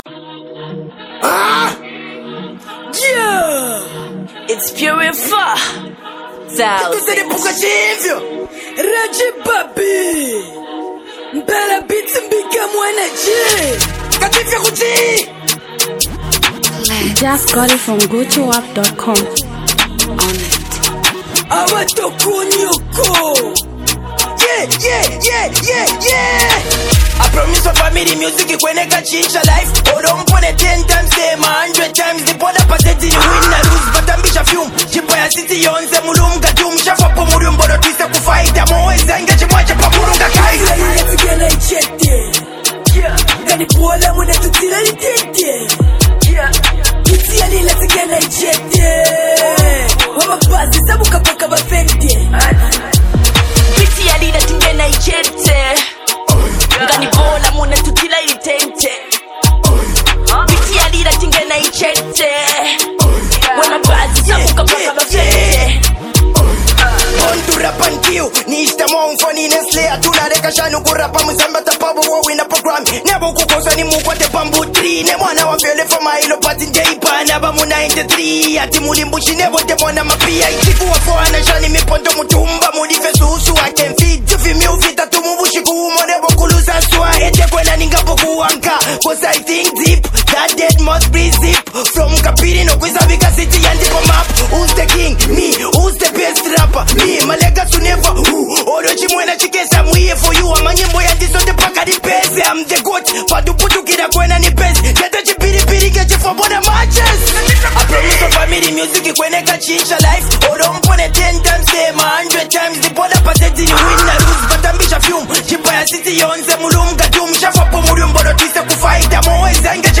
Zambian Mp3 Music
Copperbelt up talented duo hardcore rappers